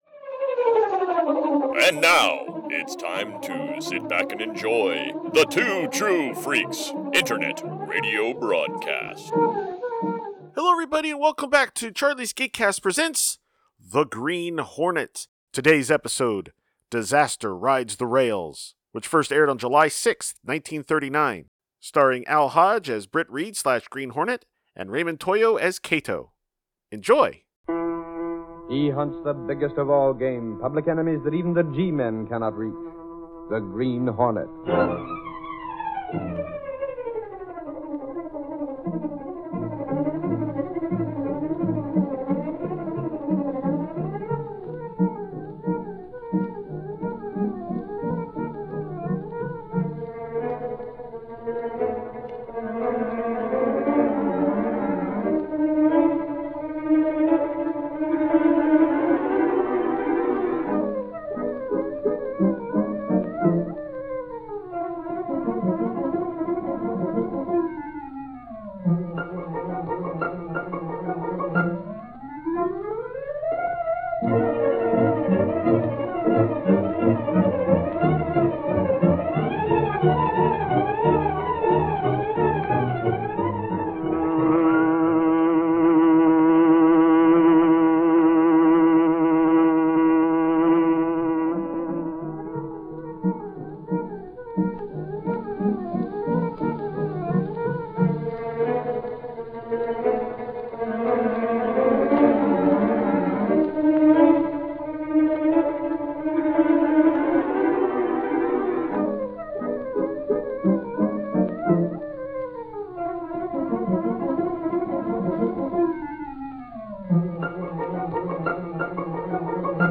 See author's posts Tagged as: Kato , The Daily Sentinel , The Black Beauty , radio series , The Green Hornet , Britt Reid . email Rate it 1 2 3 4 5